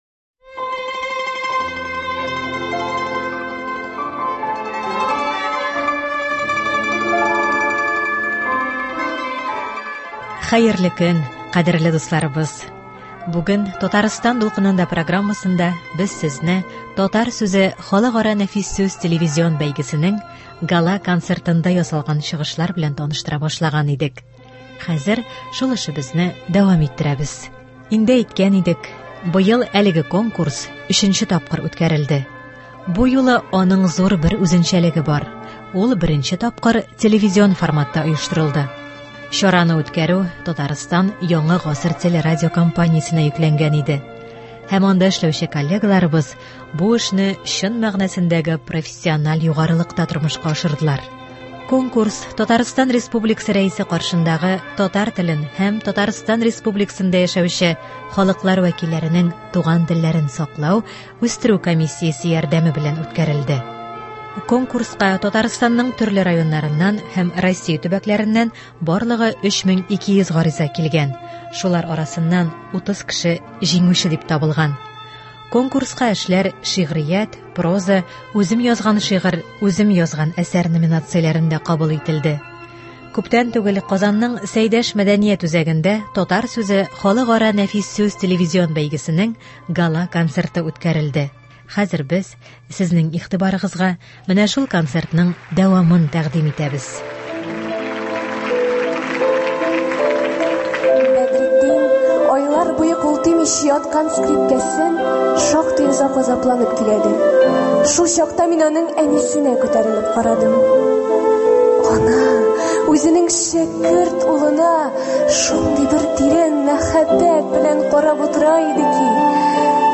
“Татар сүзе” халыкара нәфис сүз телевизион конкурсының йомгаклау концертыннан репортаж.
Хәзер без сезнең игътибарыгызга менә шул концерттан алынган язма тәкъдим итәбез.